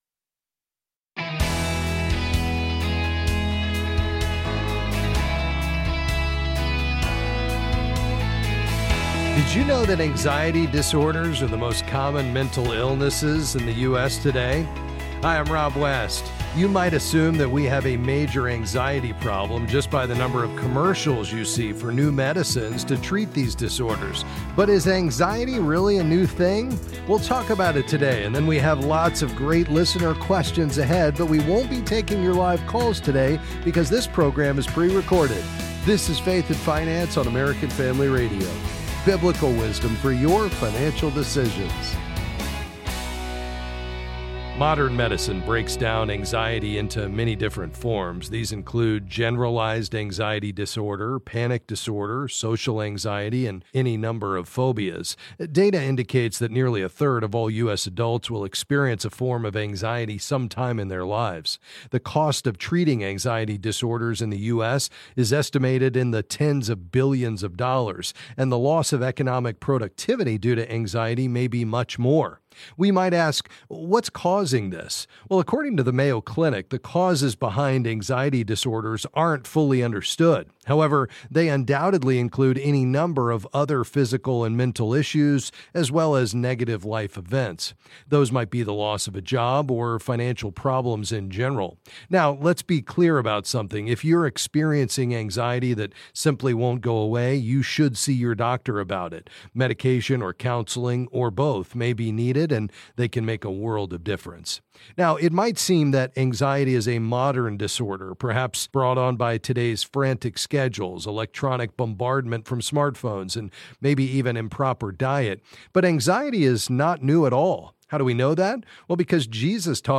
Then he answers some calls on various financial topics.